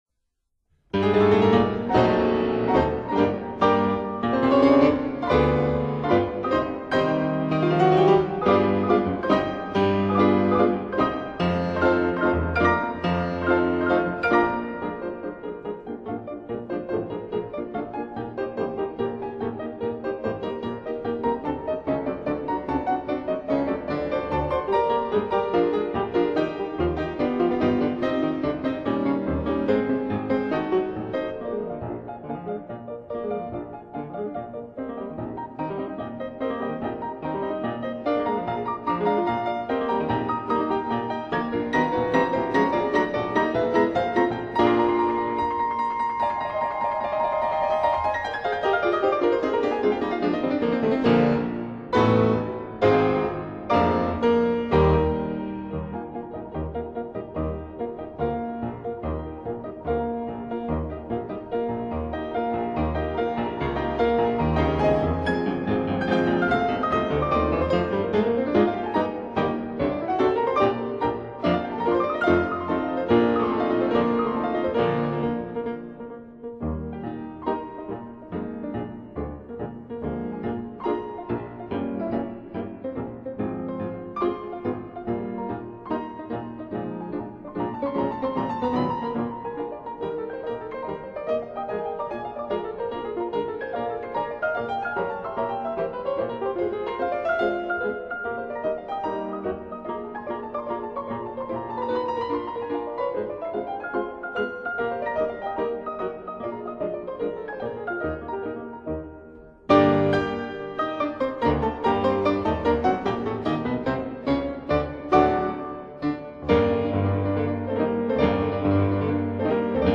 pianos